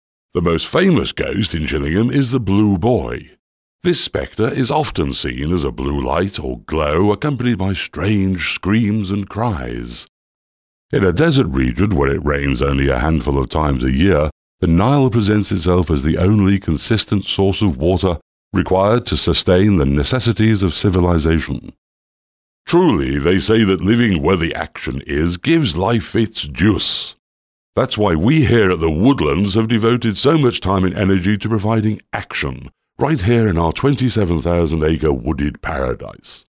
British Voiceover, English Voice Talent, Movie Trailers, Documentaries, Audiobooks, Business, Educational, Telephone, Videogames, Podcasting, Television, Internet
britisch
Sprechprobe: Sonstiges (Muttersprache):
authoritative, baritone, warm, conversational, ironic, instructional, powerful